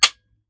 7Mag Bolt Action Rifle - Dry Trigger 003.wav